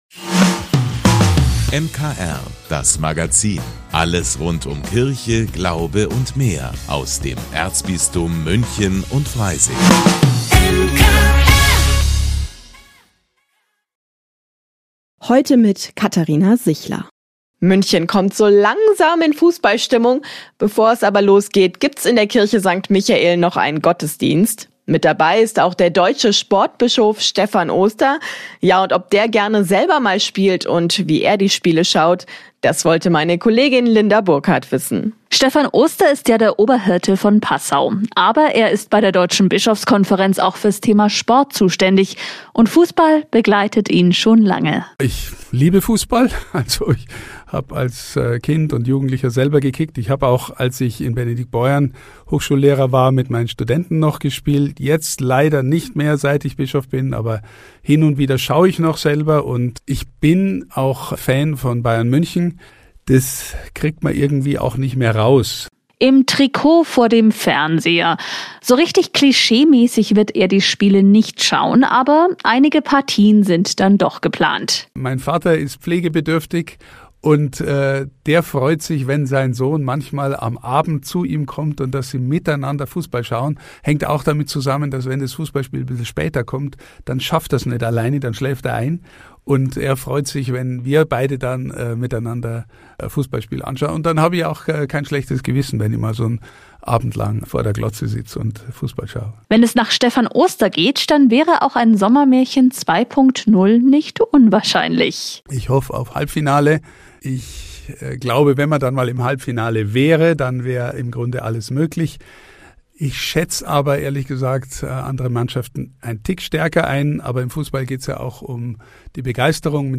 hat mit dem Passauer Bischof über seine Begeisterung für den Fußball gesprochen.